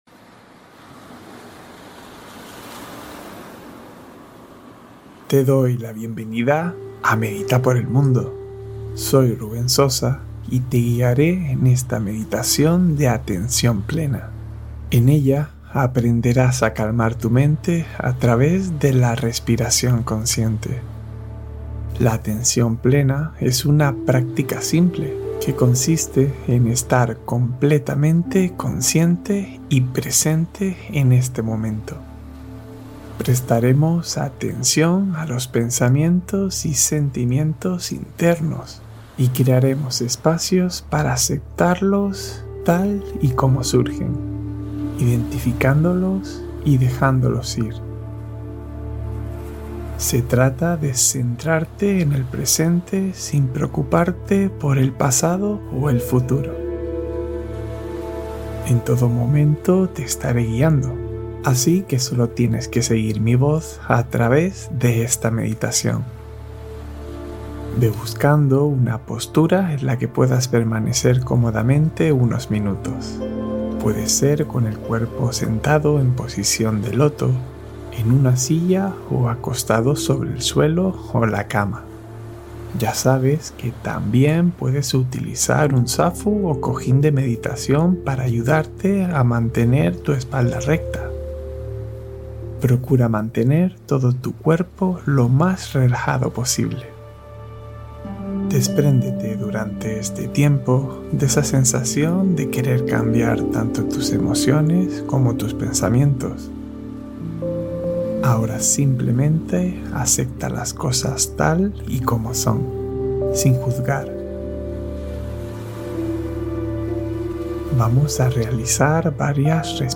Atención Plena en 10 Minutos: Meditación Guiada para Regresar al Presente